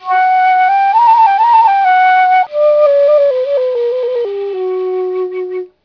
Flutes and Whistles
Hear a curved ceramic flute with a Middle-eastern scale